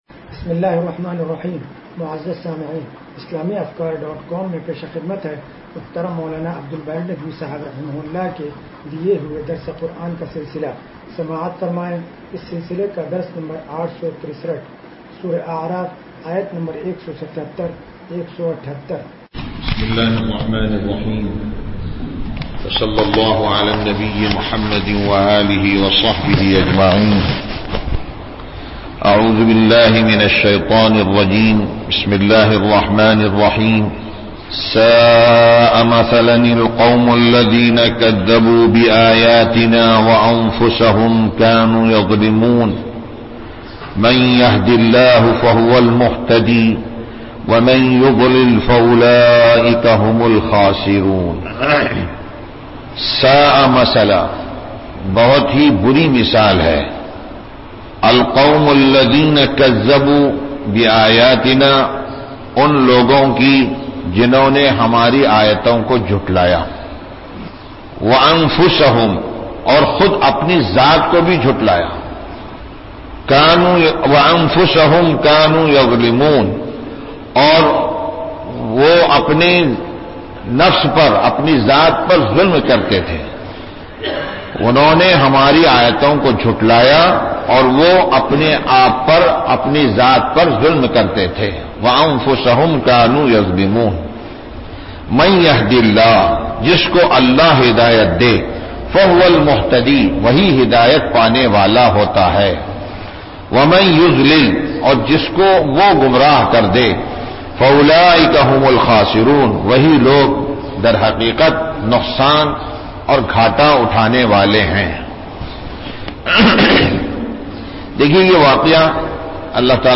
درس قرآن نمبر 0863